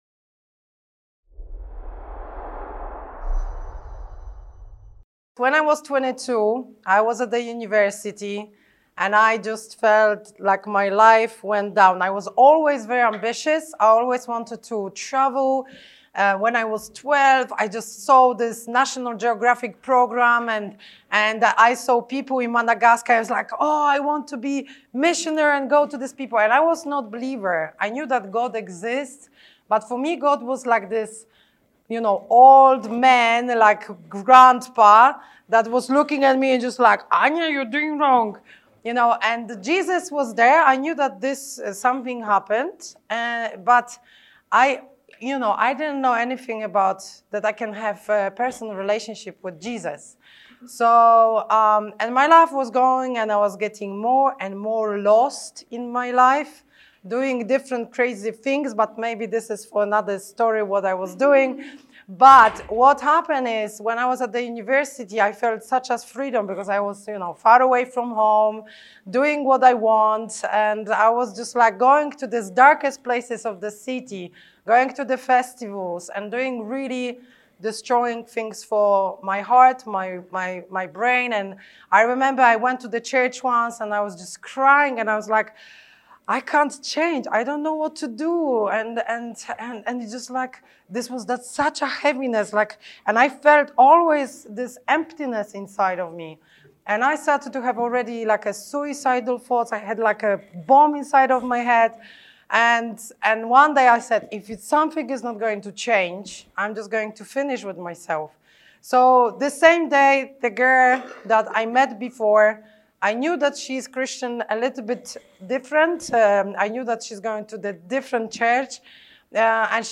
In this talk, we teach principles and models for relevantly sharing Jesus and making disciples among young people who would not usually walk into your church. How can we communicate the Gospel to them when they have such a negative view of God?